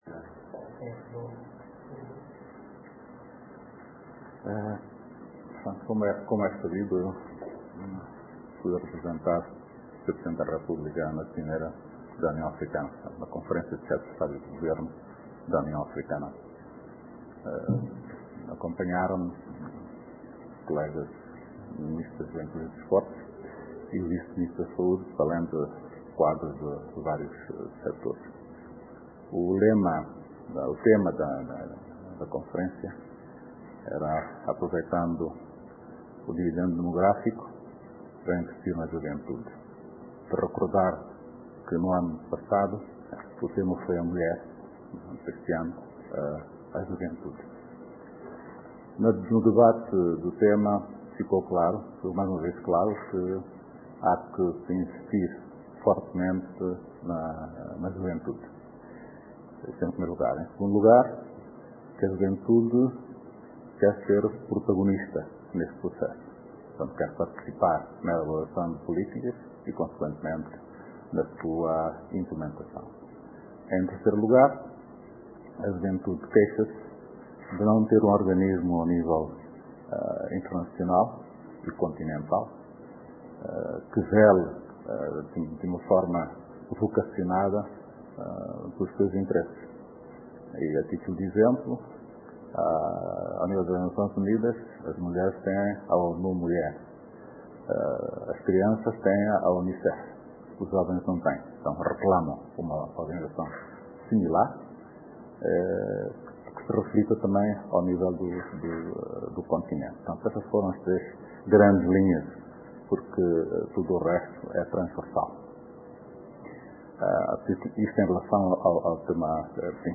Conferencia de imprensa do Ministro Baloi sobre a 28a Cimeira da UA.mp3